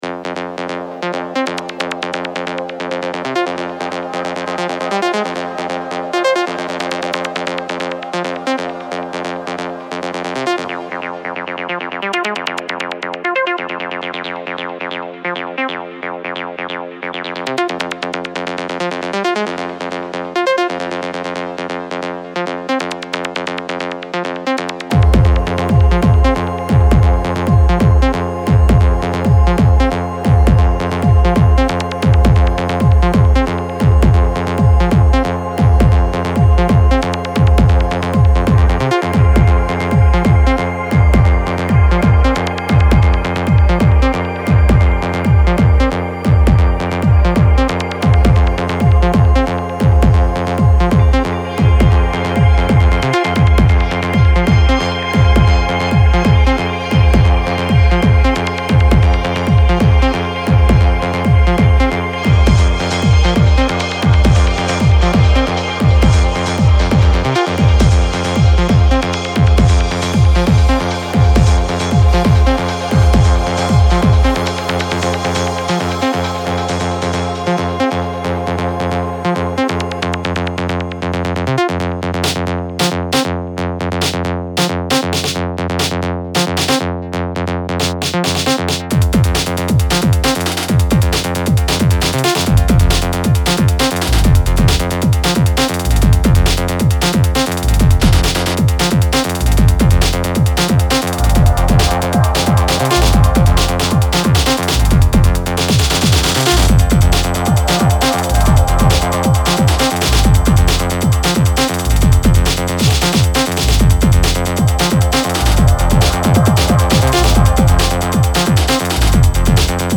Fierce & deep rolling techno works by the French producer.